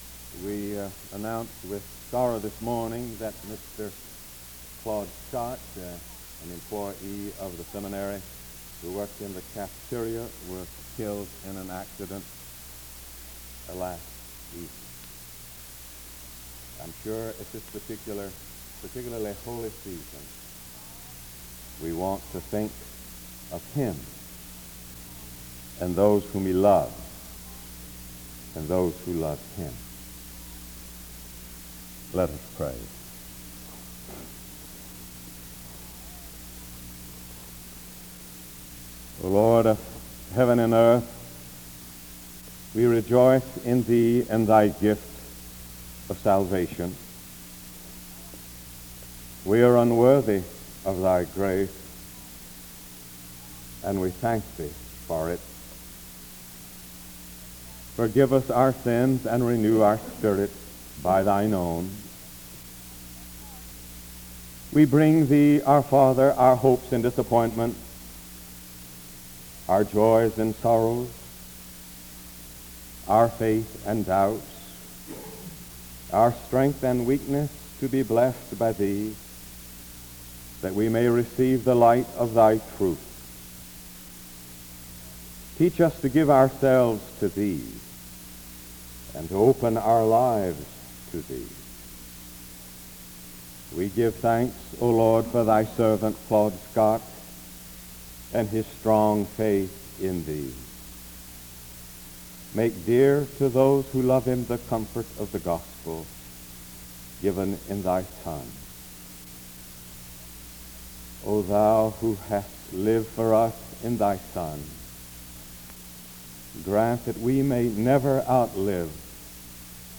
The service begins with an announcement and prayer from 0:00-2:05. Luke 23:33-43 is read from 2:18-4:42.